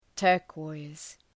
Προφορά
{‘tɜ:rkɔız}